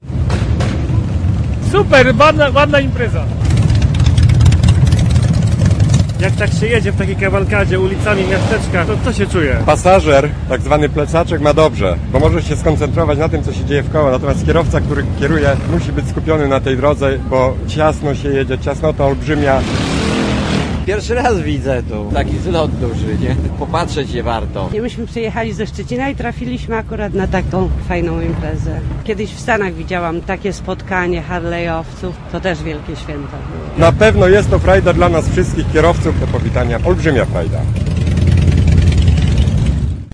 Harleye w Kórniku i Błażejewku Kilkaset ciężkich motocykli przetoczyło się po południu ulicami podpoznańskiego Kórnika. W stadzie stalowych rumaków królowały Harleye Davidsony, bo to ich zlot odbywa się w pobliskim Błażejewku. Po wrażeniem hałaśliwych maszyn - Kórnik na moment zamarł.